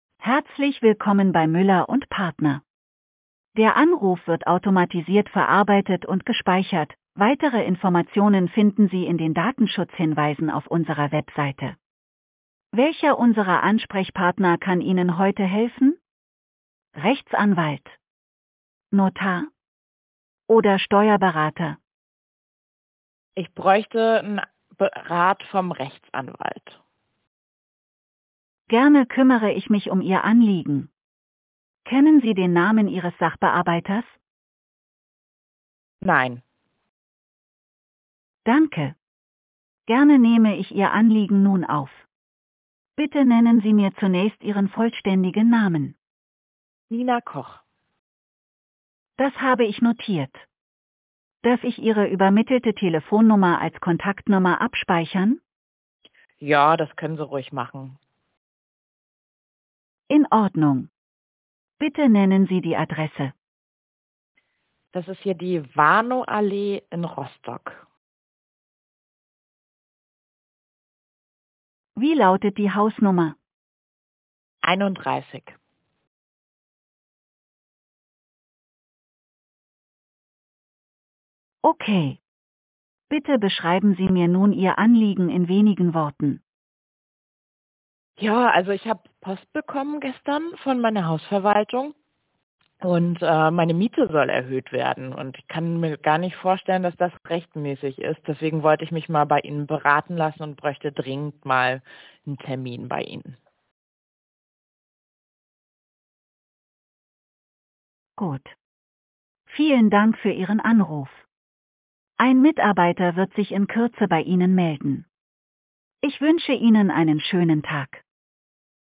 11880-Sprachbot für Rechtsanwälte & Steuerberater